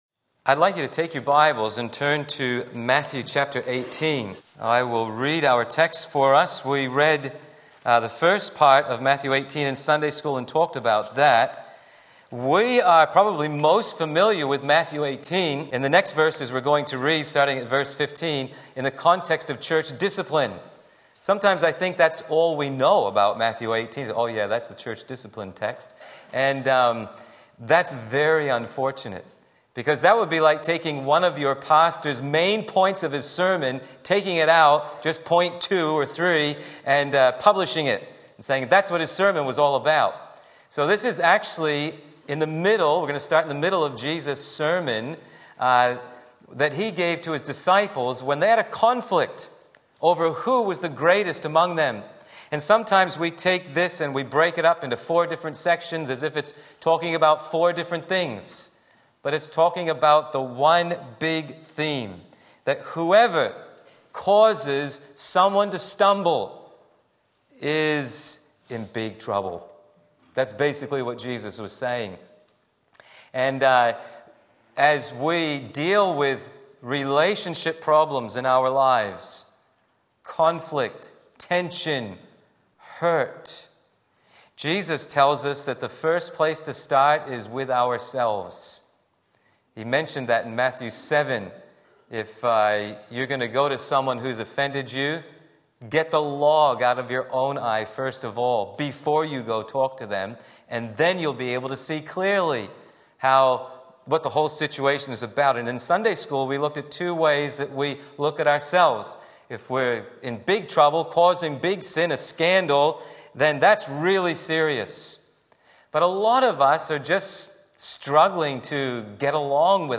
Sermon Link
Pursuing Peace by Forgiveness Matthew 18:15-35 Sunday Morning Service